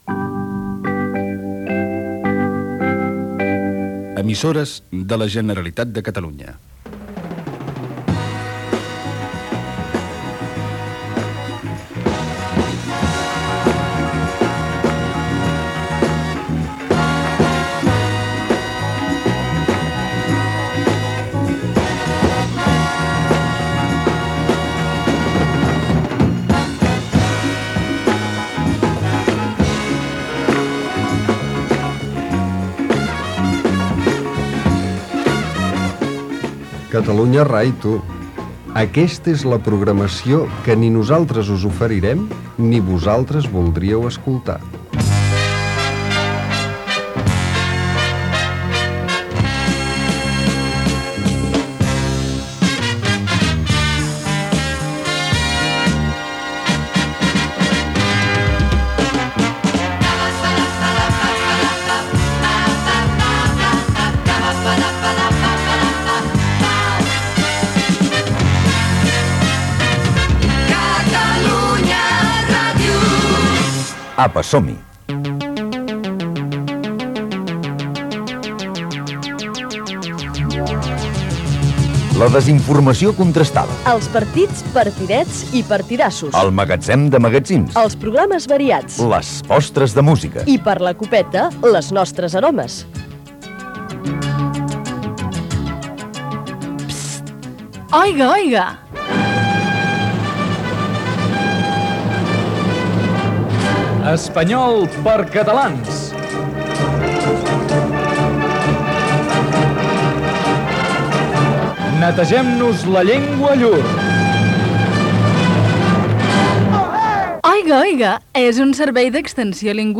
Indicatius de l'emissora, Espai humorístic "Catalunya rai" dedicat a la programació inexistent de Catalunya Ràdio